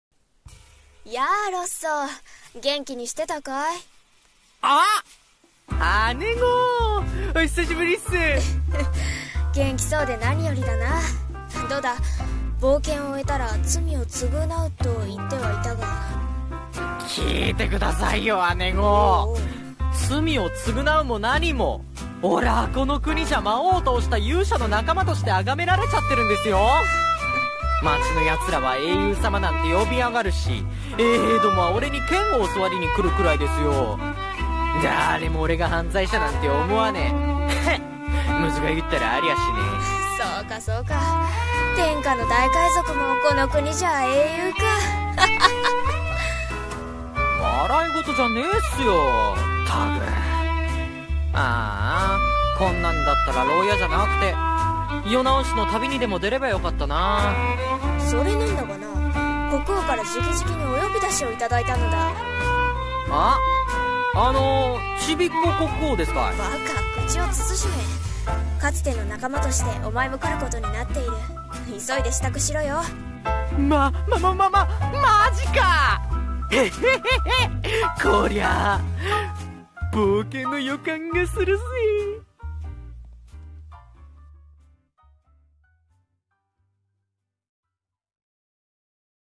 声劇台本